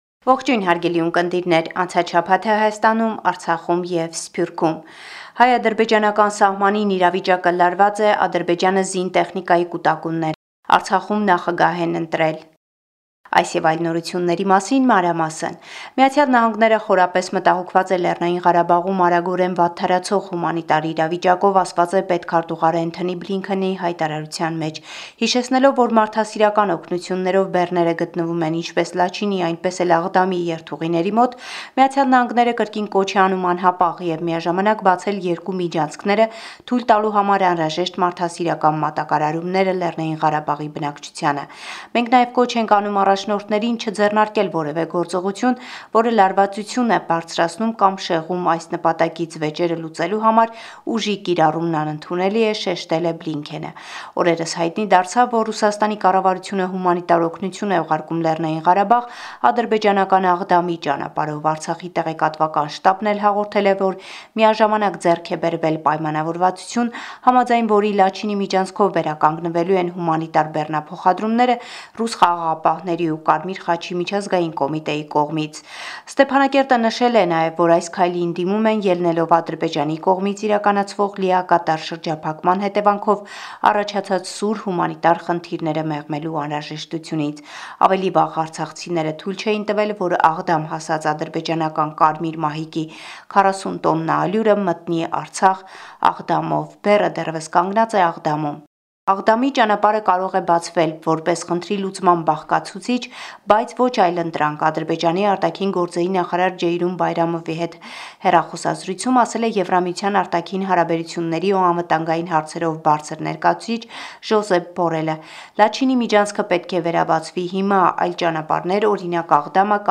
Latest News from Armenia – 12 September 2023